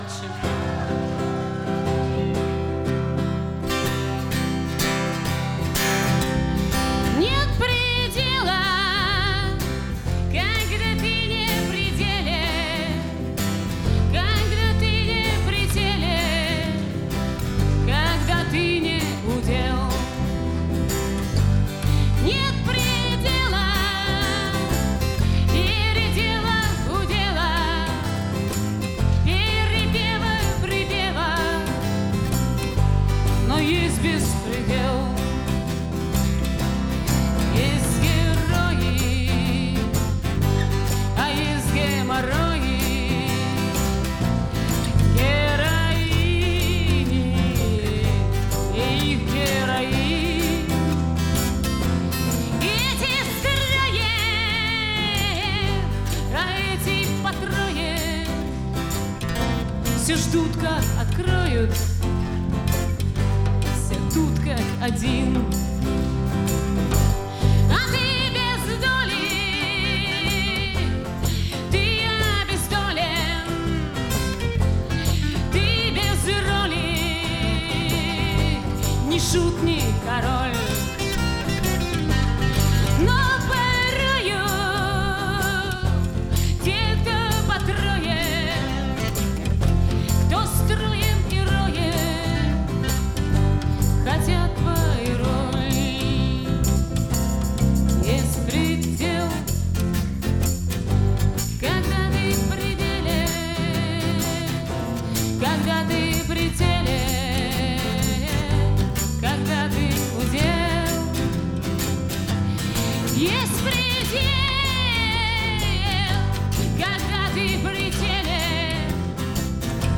Концертный диск, стиль — акустика.
бас, мандолина, голос
перкуссия
саксофон, флейта
гитара